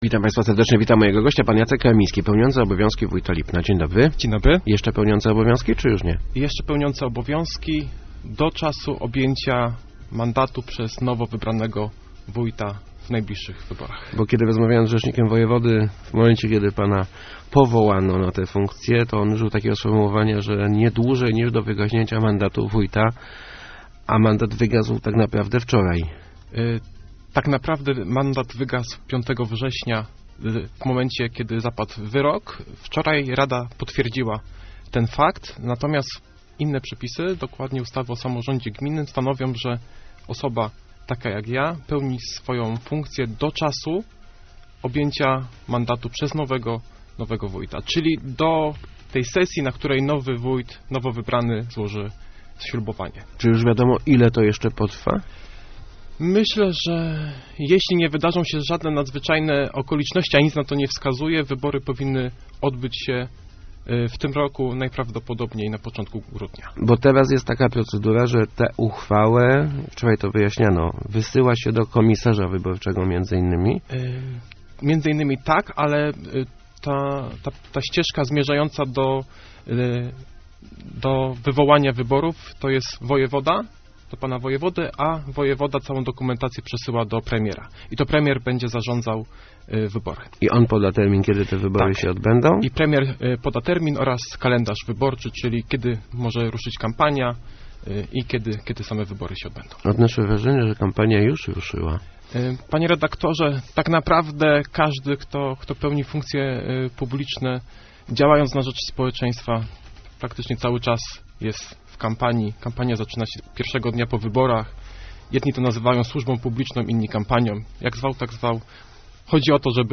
Jeszcze nie podjąłem decyzji - mówił w Rozmowach Elki Jacek Karmiński, pełniący obowiązki wójta Lipna, zapytany o swój start w wyborach na wójta. Przyznał, że wiele osób namawia go do tego kroku.